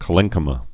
(kə-lĕngkə-mə)